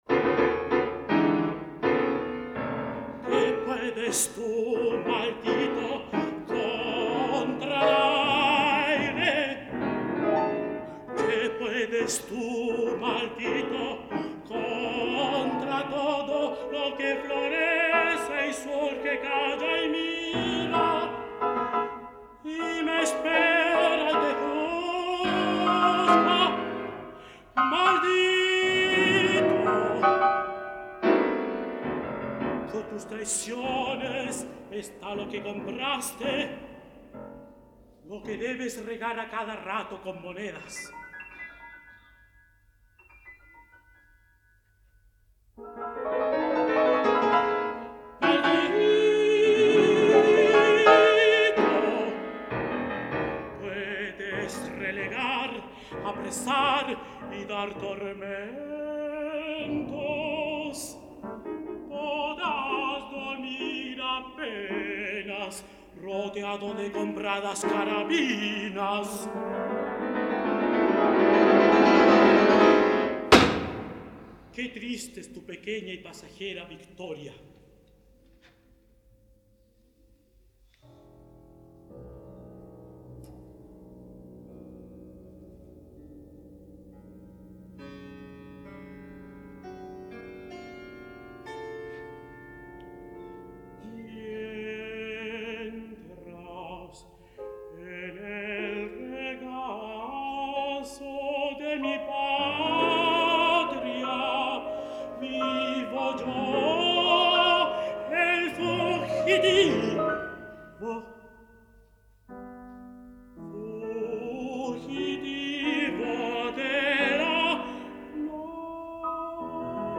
El Fugitivo: Imprecación y Presencia para tenor y piano